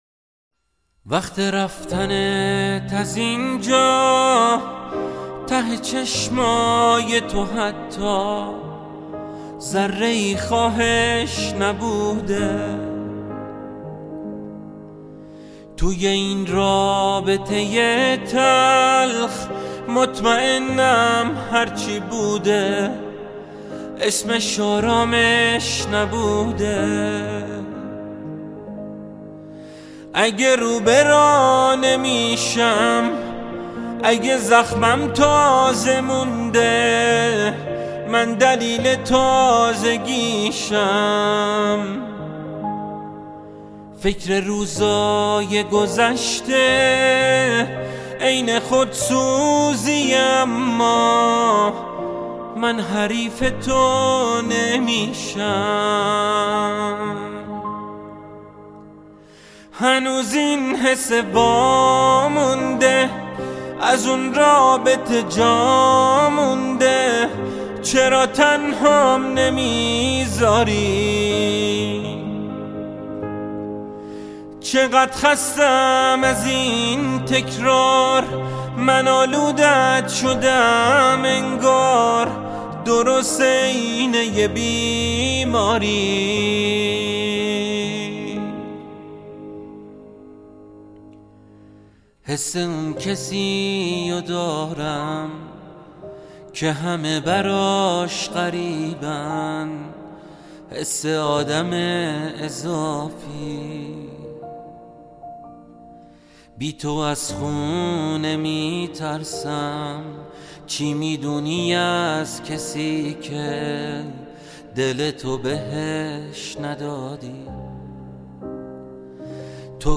(Piano Version)